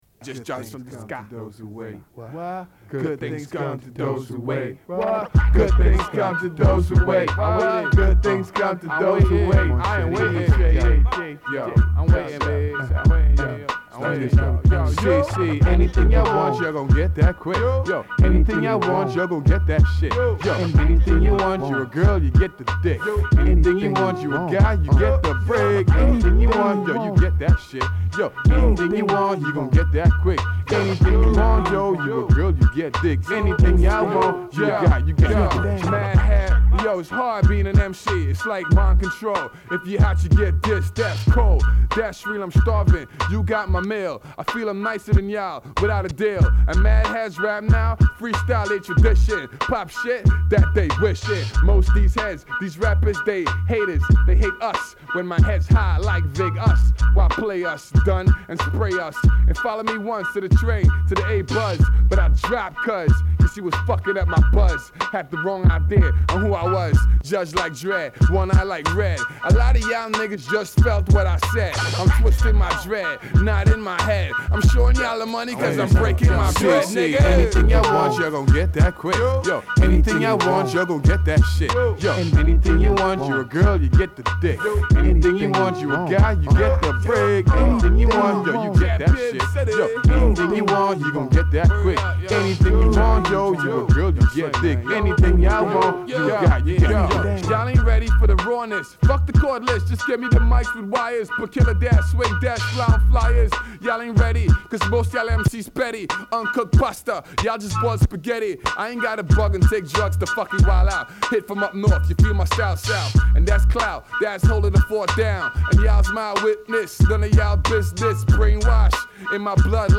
Rap
Not mastered tracks (yet)